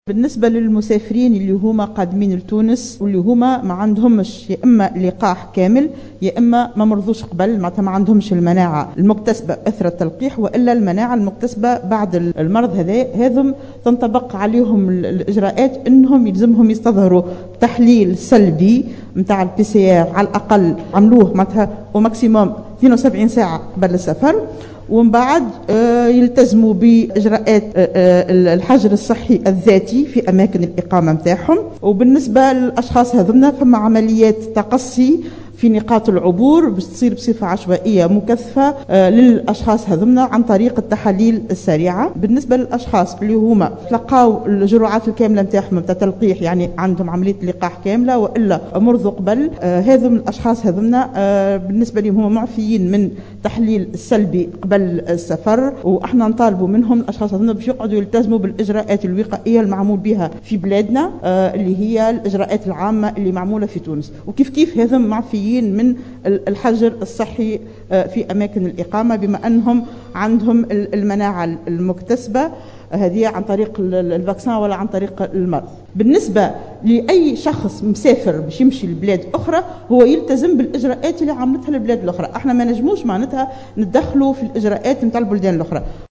وقالت بن علية خلال ندوة صحفية إثر اجتماع اللجنة الوطنية لمجابهة الكورونا، إنه بالنسبة للأشخاص الذين أصيبوا بالكورونا وتماثلوا للشفاء أو من تلقوا الجرعات الكاملة للتلاقيح، يقع إعفاؤهم من الاستظهار بالتحليل السلبي قبل السفر ومن الحجر الصحي الذاتي في أماكن الإقامة نظرا لاكتسابهم المناعة من الفيروس، مع الالتزام بالإجراءات الوقائية المعمول بها في تونس.